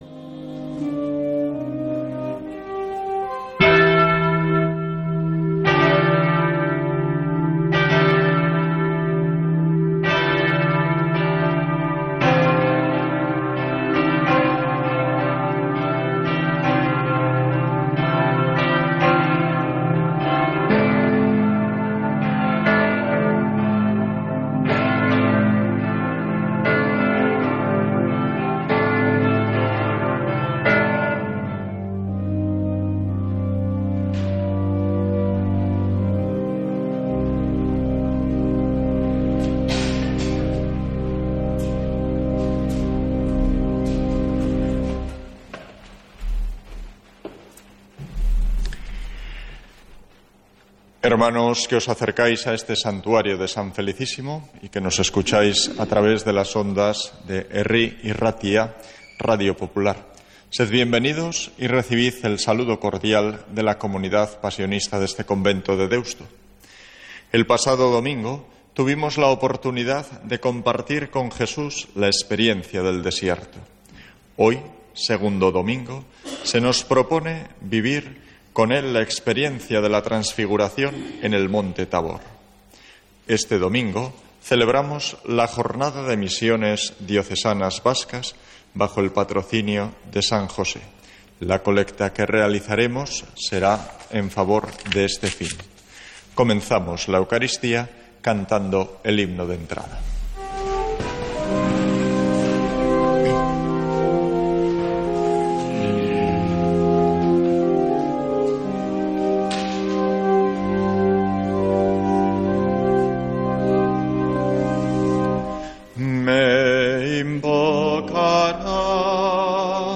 Santa Misa desde San Felicísimo en Deusto, domingo 16 de marzo